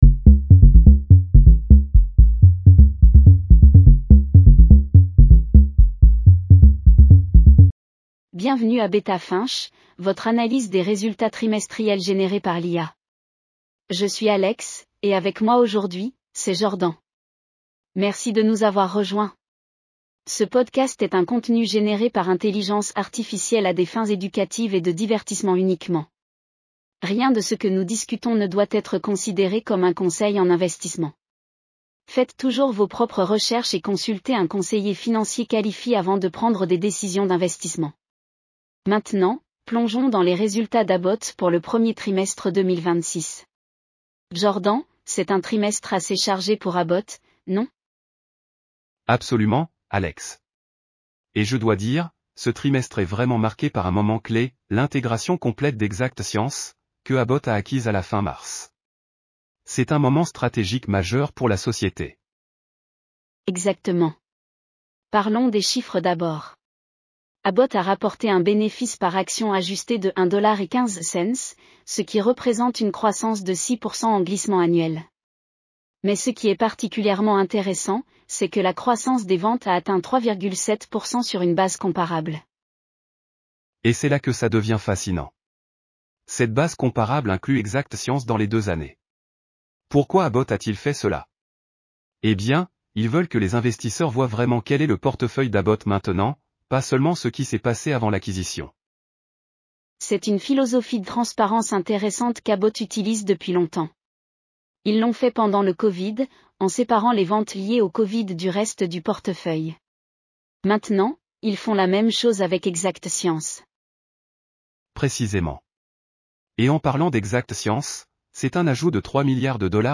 Bienvenue à Beta Finch, votre analyse des résultats trimestriels générée par l'IA.